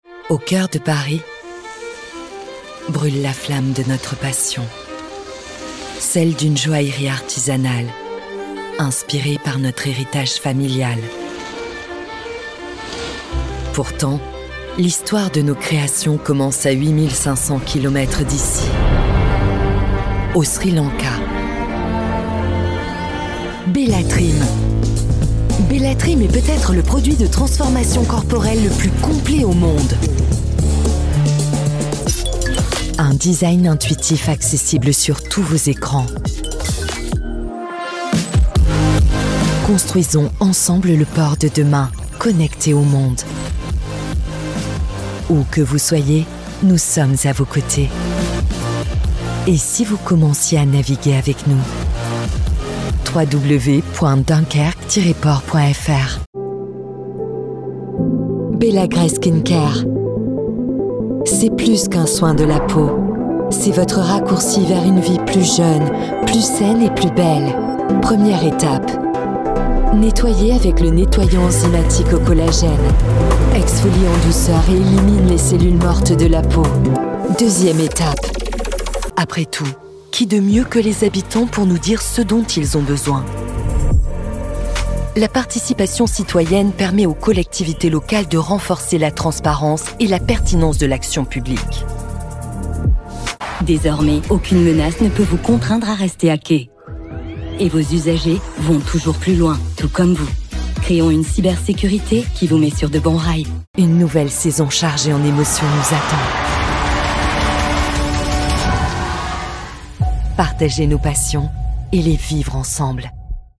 Profonde, Naturelle, Distinctive, Accessible, Polyvalente
Corporate